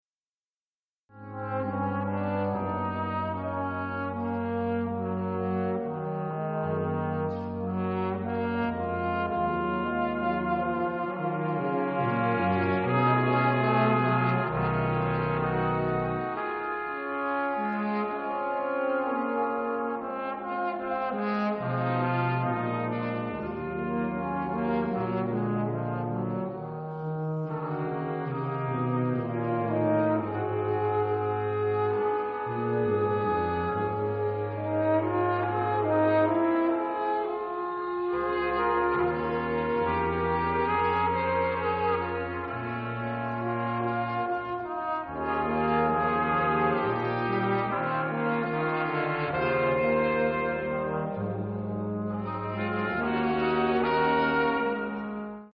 St. Rose Concert Series 2006
Polished Brass
St. Rose Catholic Church